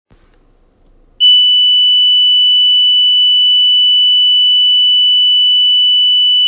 発振音 連続,音圧75dB（0.3m)
発振周波数3.0±0.5kHz
圧電ブザー